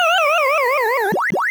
pacdie1.wav